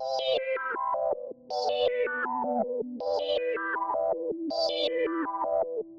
BackYard Melody 1 (FilterFreak 2).wav